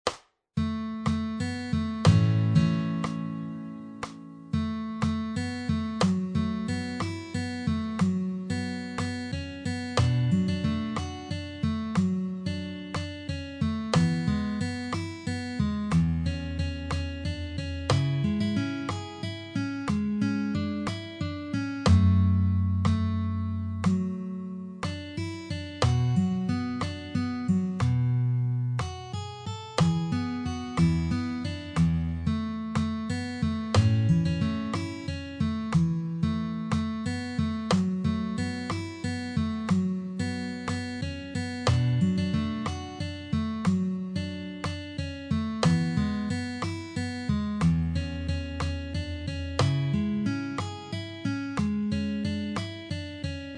Audio anteprima chitarra